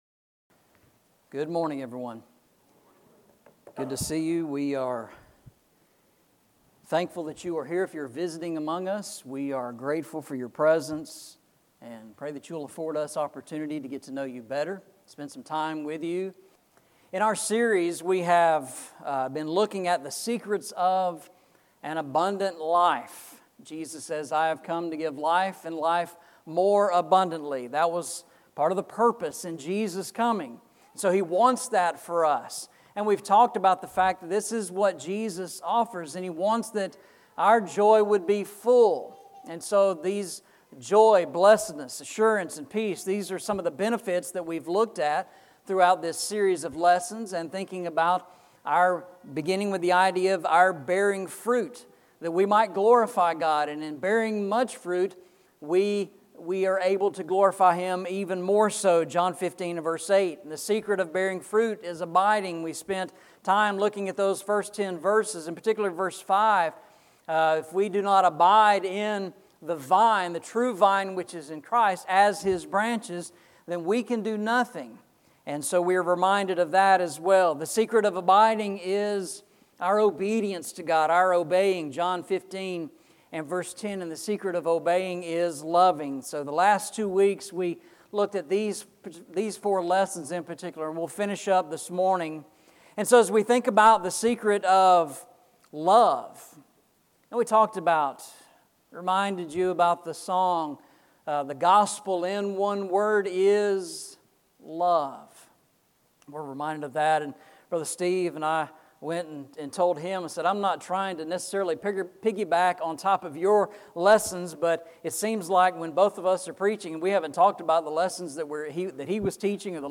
Series: Eastside Sermons
Psalm 1:1-3 Service Type: Sunday Morning « Sermon on the Mount